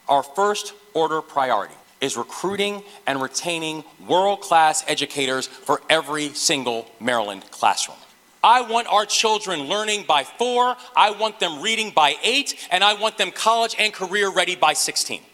One of the three pillars that Governor Moore featured in his State of the State speech on Tuesday was the state’s investment in its people.